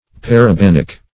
Search Result for " parabanic" : The Collaborative International Dictionary of English v.0.48: Parabanic \Par`a*ban"ic\, a. [Gr.